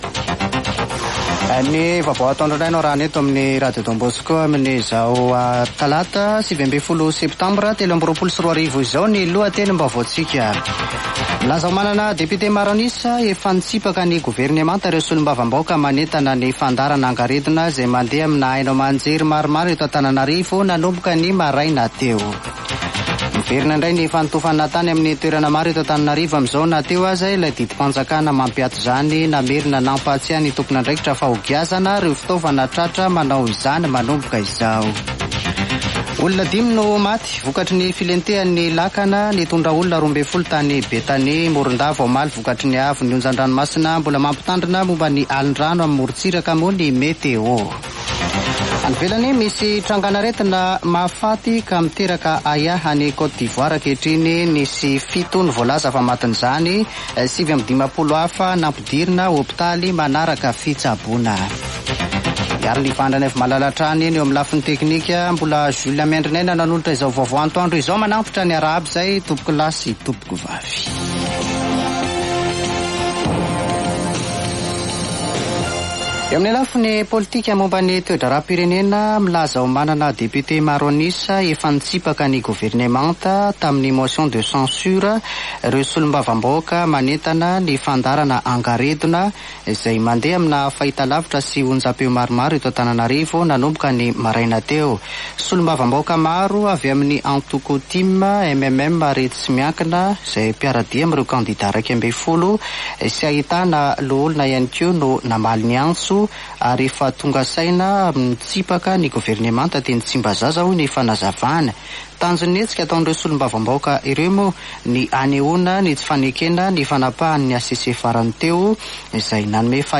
[Vaovao antoandro] Talata 19 septambra 2023